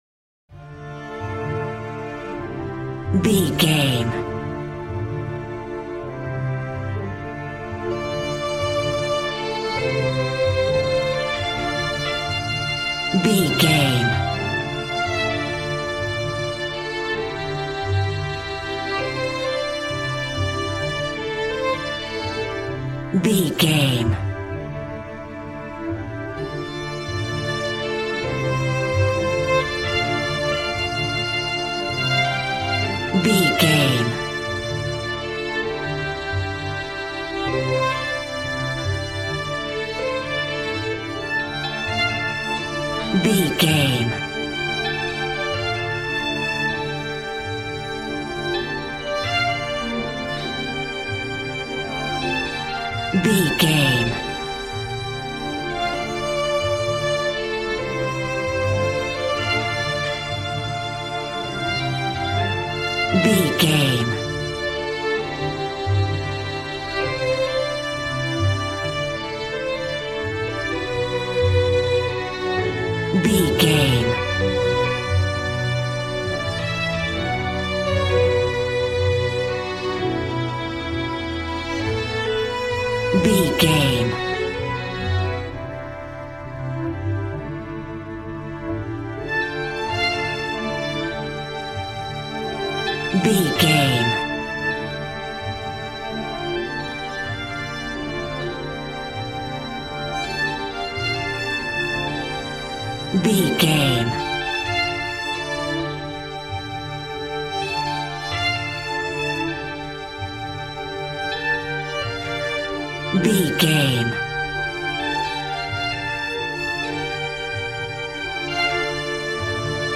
Aeolian/Minor
joyful
conga
80s